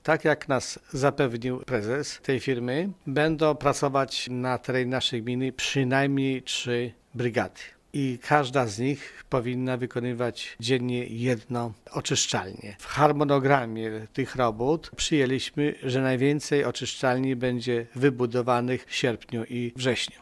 Mimo to jeszcze w tym roku ma powstać więcej niż połowa planowanych oczyszczalni – informuje zastępca wójta Wiktor Osik: